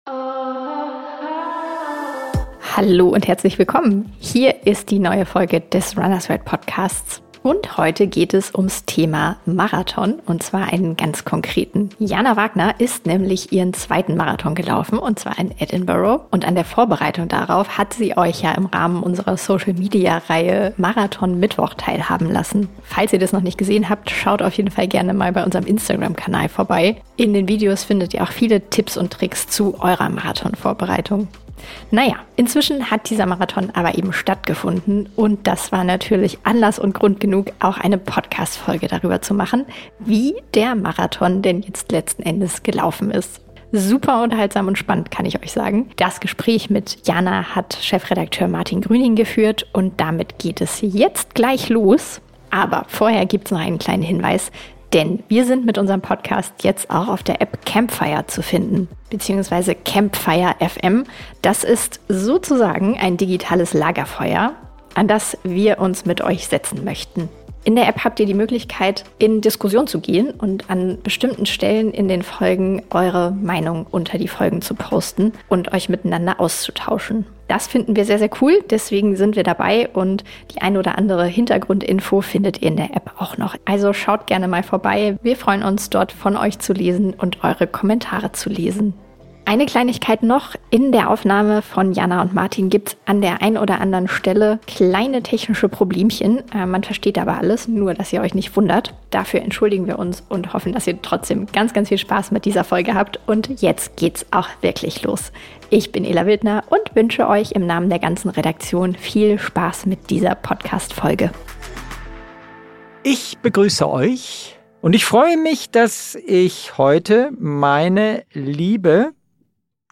1 REISELUST - Virginia - Auf der Crooked Road mit viel Musik durch den US-Bundesstaat 57:05
Freuen Sie sich auf eine Tour entlang der Crooked Roads in South-West Virginia. Uns erwartet eine Tour entlang geschwungener Straßen auf den Spuren von Country Musik und Geschichten rund um den Blue Ridge Parkway.